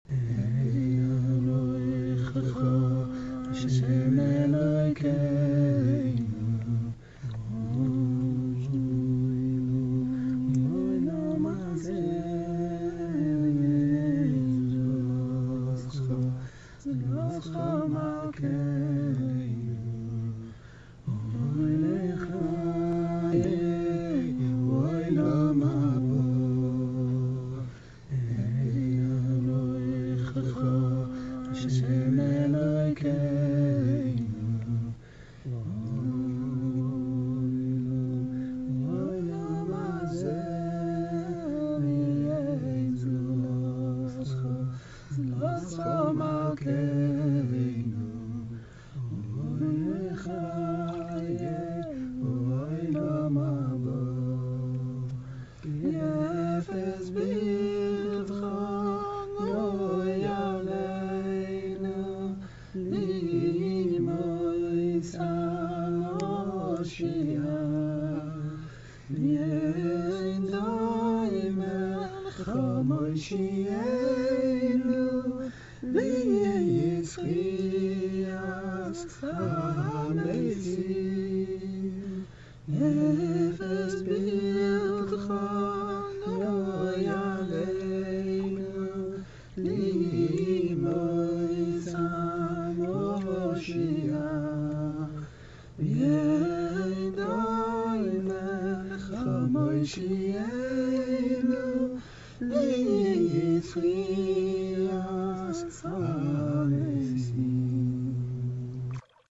The singers were a group of wonderful X-O's from the Chasidic world living in the Bay area.
Listen for the high point of this nigun at Moshi'einu, our Saviour. The beautiful resonance comes I think from the water surrrounding us. It turns out that a hot tub in Berkeley is an amazing place to collect nigunim. (You can hear little water splashes in a few places.)